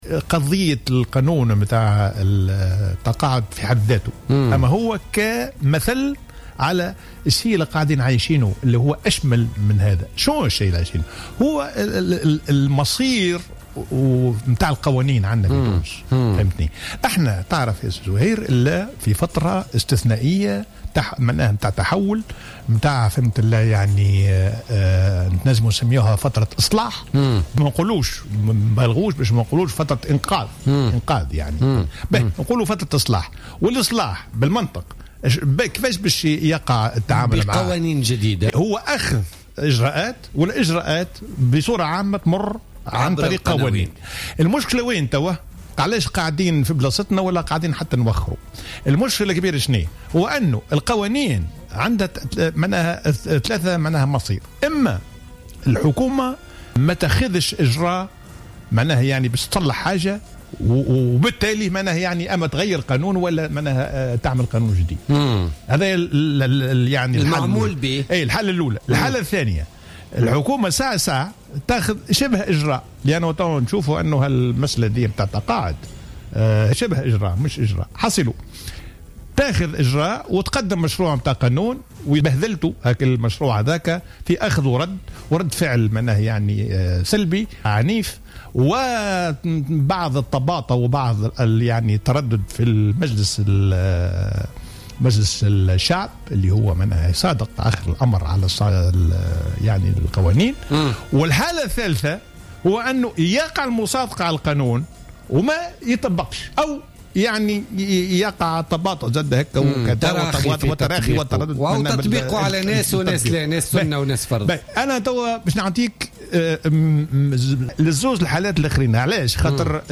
أكد الخبير الاقتصادي ووزير المالية السابق حسين الديماسي في تصريح للجوهرة أف أم اليوم الجمعة 8 أفريل 2016 أن مشروع قانون الترفيع في سن التقاعد لن يحل أزمة الصناديق الاجتماعية رغم أنه يمكن أن يؤخرها.